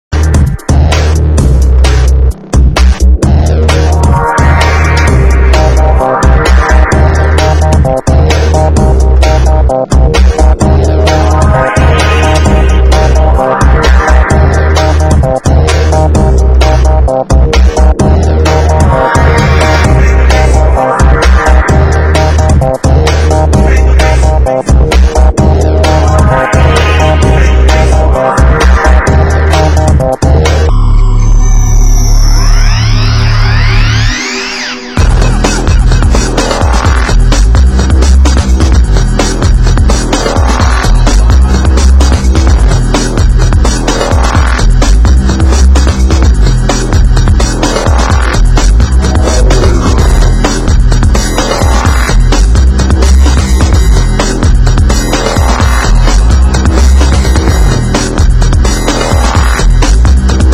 Genre: Nu Skool Breaks